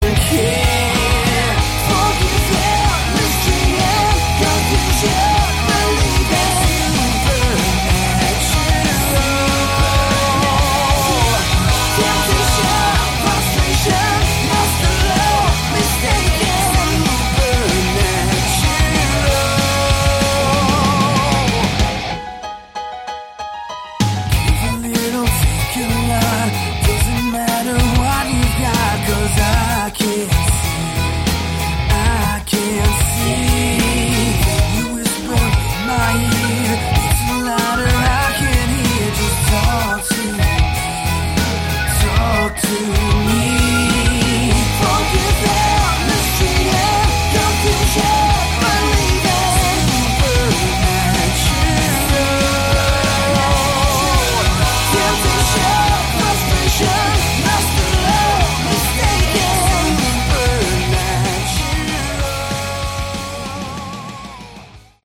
Category: Melodic Rock
keyboards, vocals
bass, vocals
drums
vocals, guitar